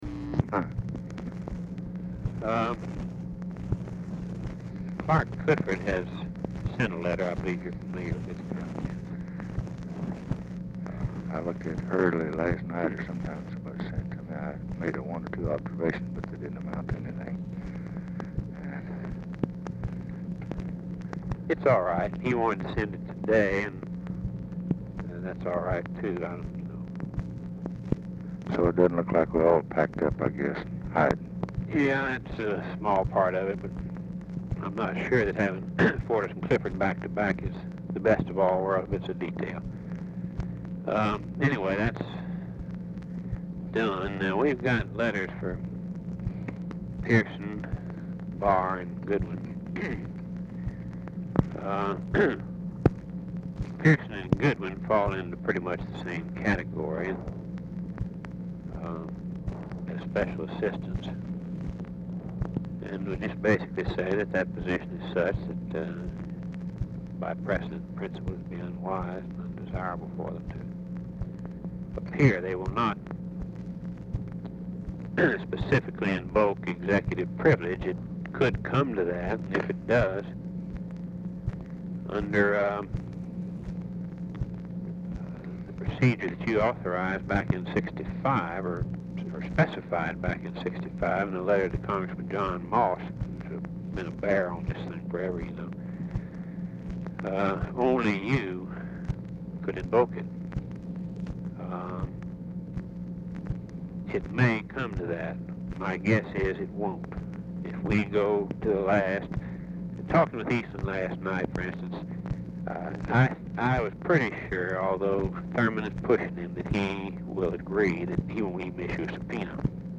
Telephone conversation # 13413, sound recording, LBJ and RAMSEY CLARK, 9/12/1968, 5:16PM | Discover LBJ
Format Dictation belt
Location Of Speaker 1 Mansion, White House, Washington, DC